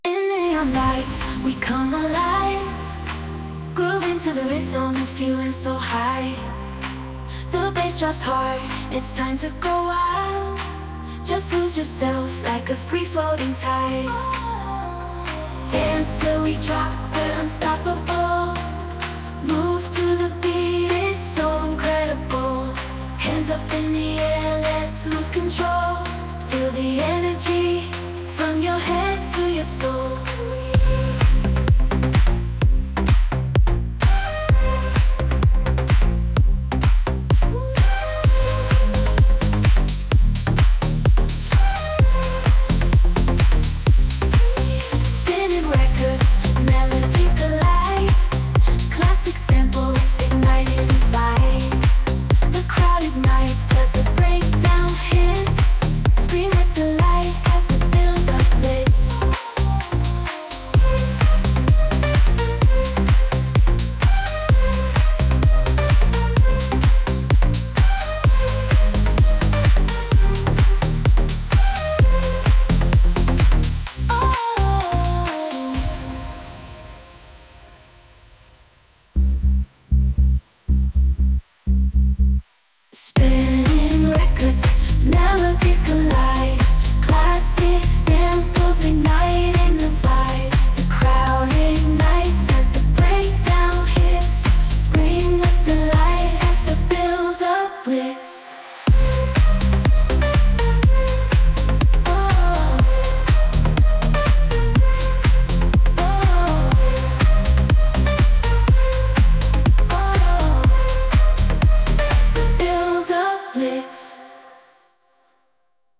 AI Pop / Dance
Experience the best of AI-generated pop music.